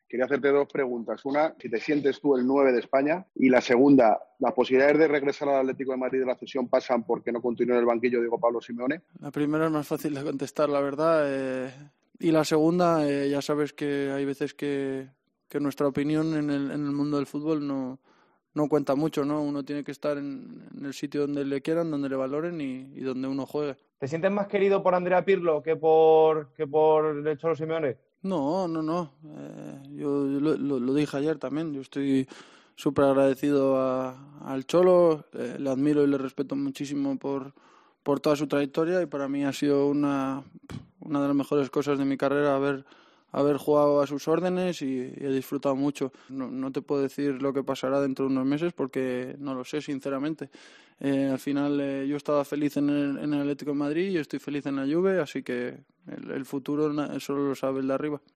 El delantero de la Selección compareció ante los medios de comunicación en la rueda de prensa previa al partido de la Selección ante Grecia.